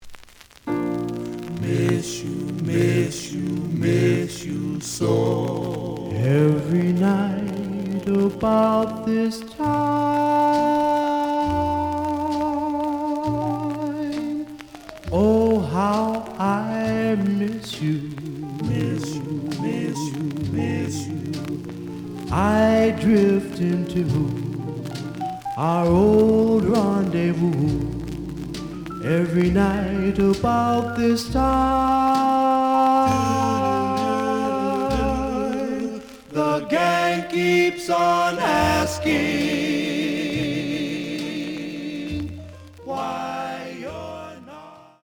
The audio sample is recorded from the actual item.
●Genre: Rhythm And Blues / Rock 'n' Roll
Slight cloudy on B side.